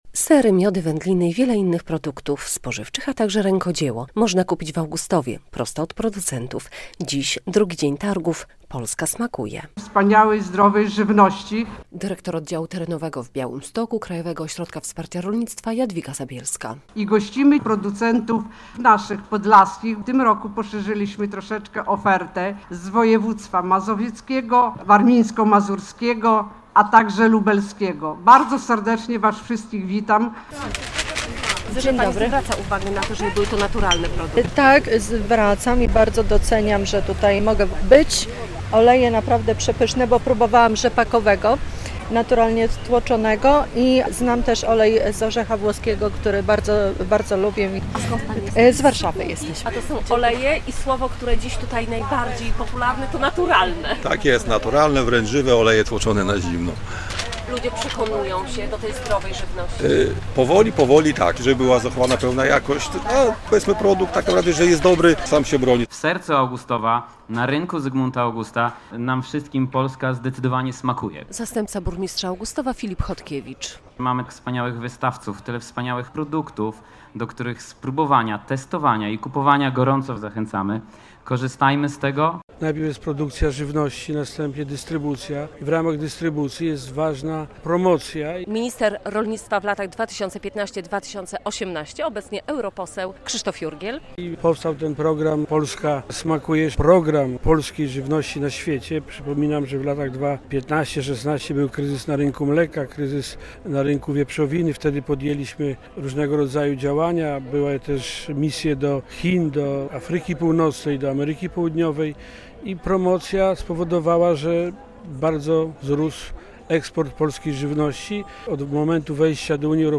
Dwudniowe targi "Polska smakuje" w Augustowie - relacja
Targi "Polska smakuje" zorganizowano na Rynku Zygmunta Augusta w Augustowie.